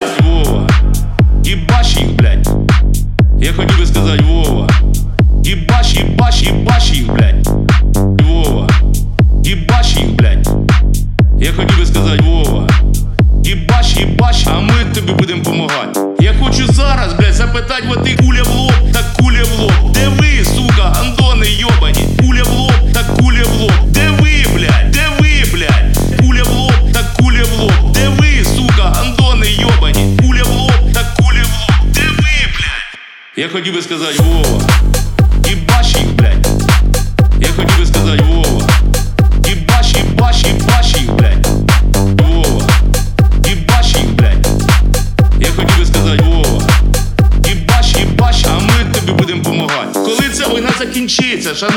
Архив Рингтонов, Клубные рингтоны